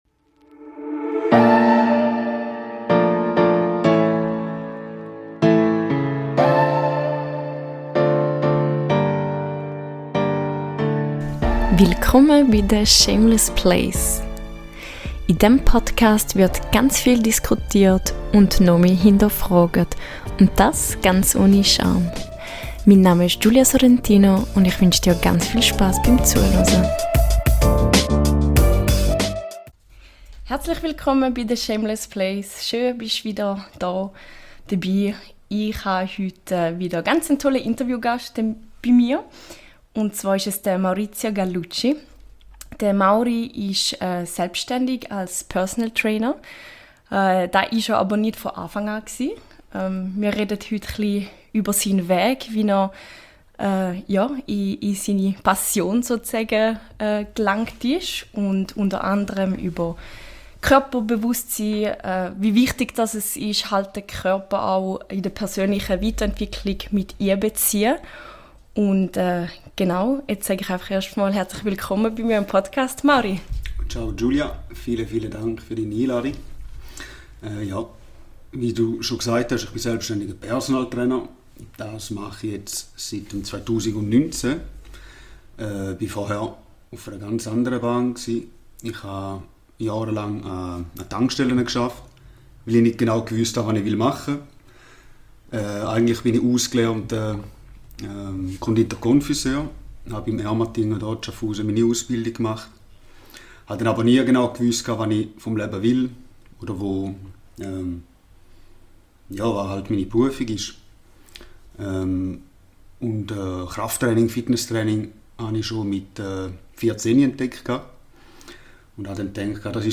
Dieser Podcast befasst sich unter anderem mit Themen, welche in der Gesellschaft als Tabu gelten. Dabei werden Menschen interviewt, die ihre einzigartigen und persönlichen Lebensgeschichten erzählen.